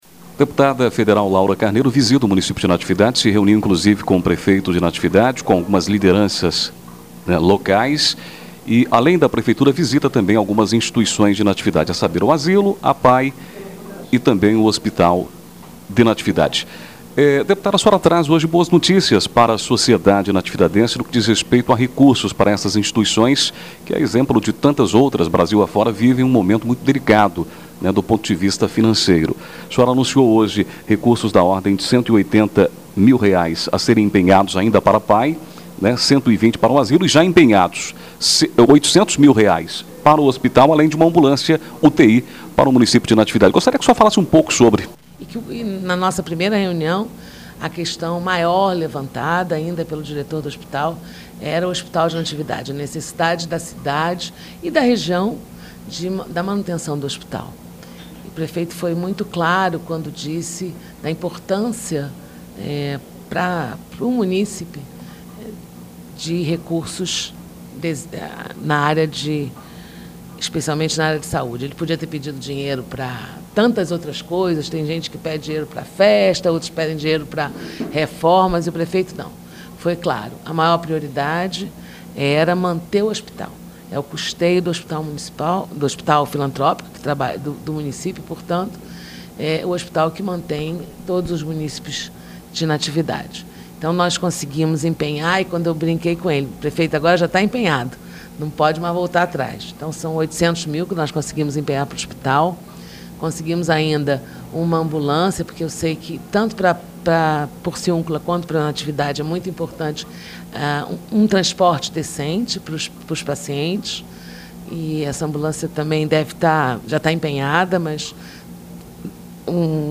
4 maio, 2018 ENTREVISTAS, NATIVIDADE AGORA
ENTREVISTA-LAURA-CARNEIRO-SITE.mp3